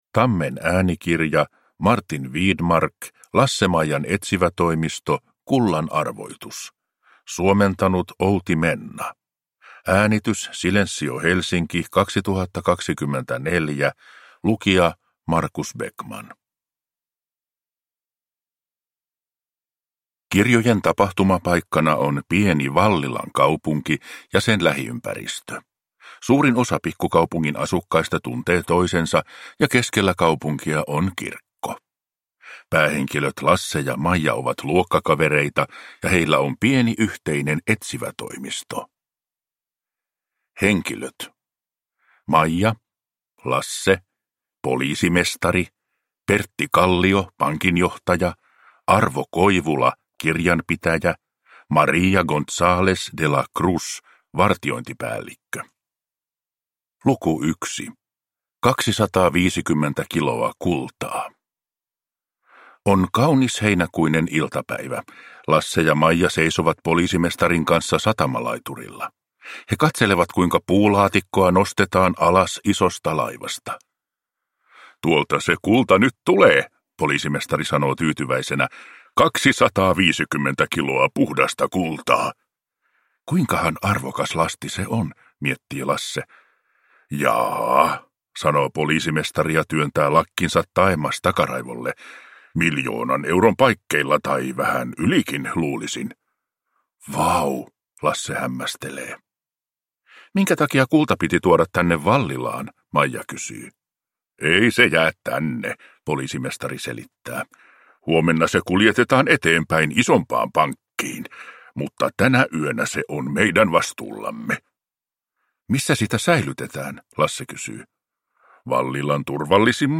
Kullan arvoitus. Lasse-Maijan etsivätoimisto – Ljudbok